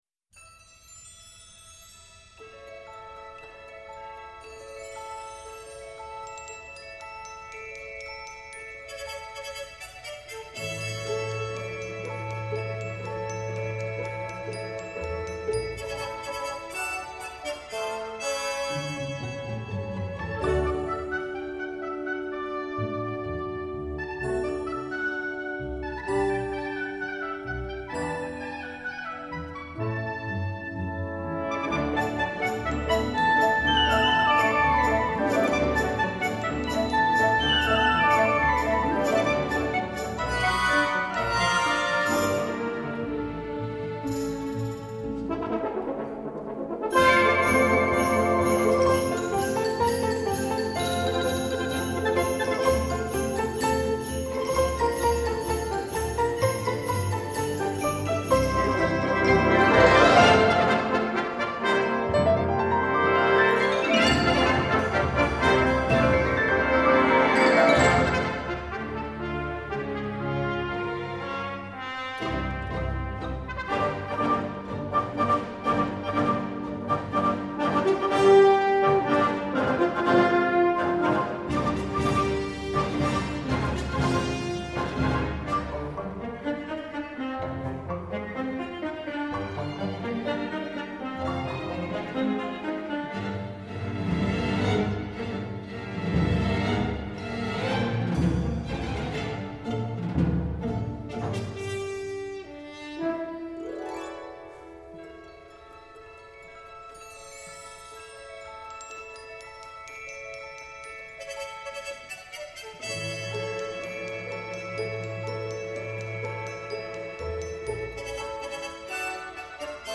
Après, ce sont des variations moches au synthé.